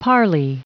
Prononciation du mot parley en anglais (fichier audio)
Prononciation du mot : parley